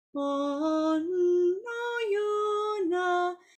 The same happens at “bo-n” in the third line.